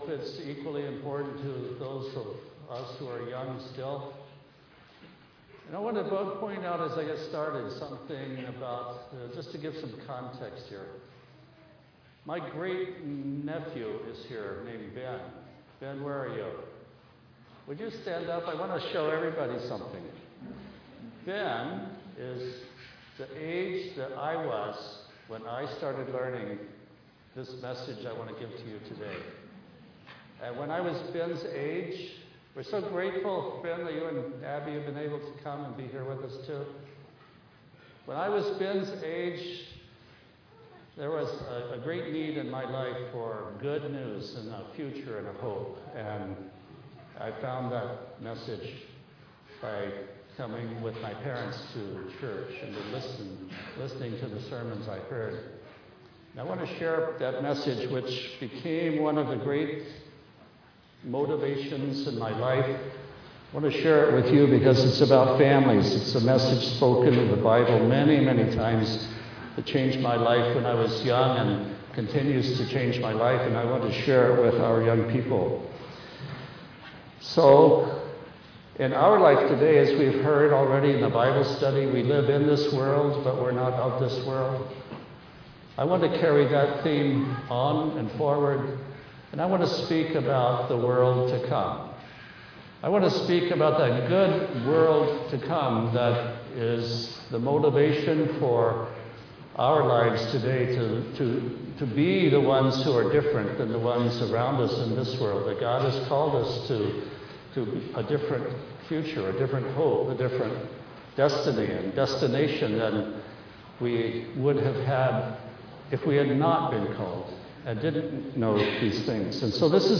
Second Message at Tacoma's Family Weekend
Given in Tacoma, WA